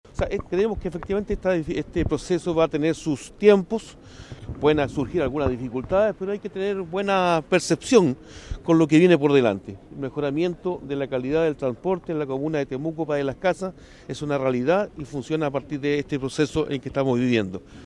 Por su parte, el alcalde de Padre Las Casas, Mario González, llamó a la ciudadanía a tener una buena percepción con la modernización del transporte público.